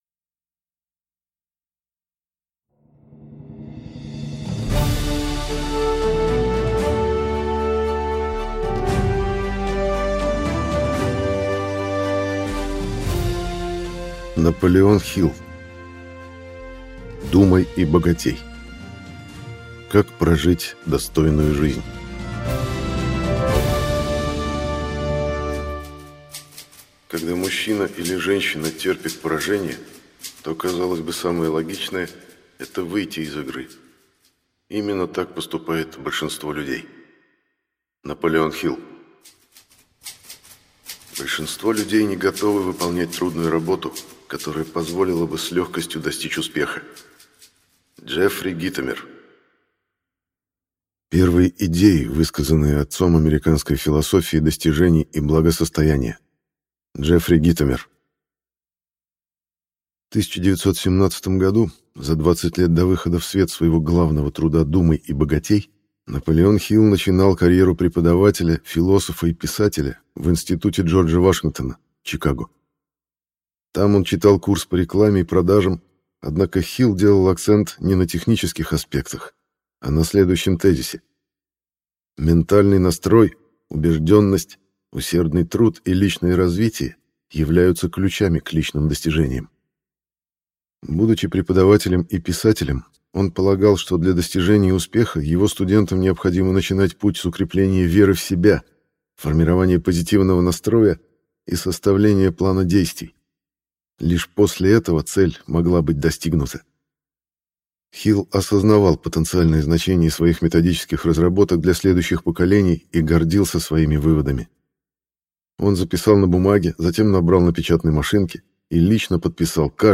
Аудиокнига Думай и богатей: как прожить достойную жизнь | Библиотека аудиокниг